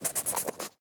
sounds / mob / fox / sniff2.ogg
sniff2.ogg